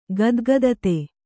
♪ gadgadate